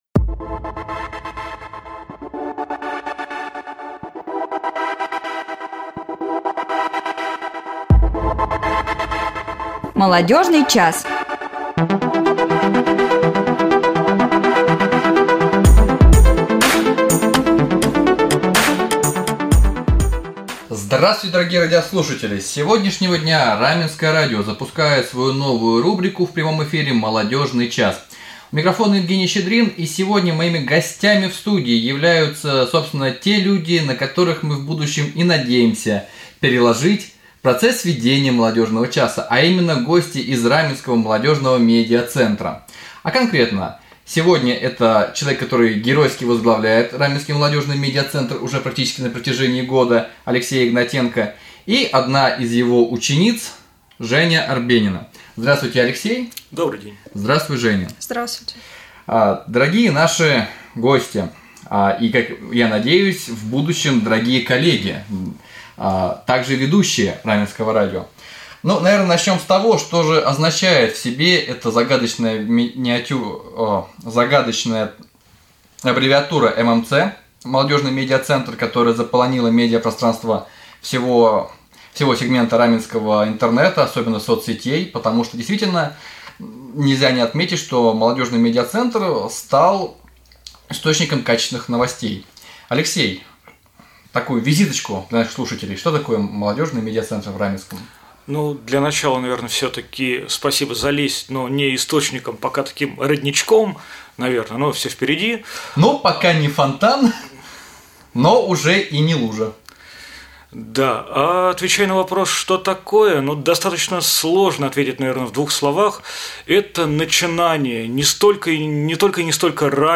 Юные корреспонденты Раменского молодежного медиа-центра открывают свой взгляд на актуальные события района и области, рассказывают новости и беседуют с интересными людьми в прямом эфире!